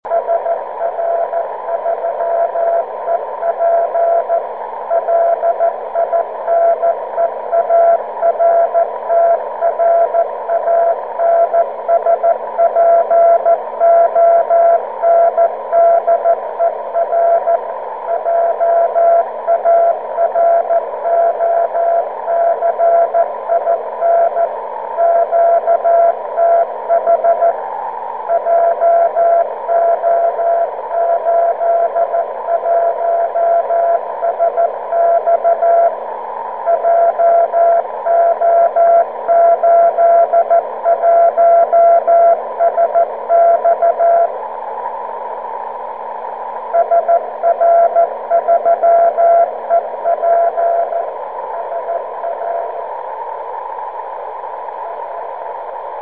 słychać w nienajlepszych warunkach transponder liniowy SR3EP (JO81SX) w Sicienku (JO83VE).
Bikon z nagrania ma tylko 1 W mocy.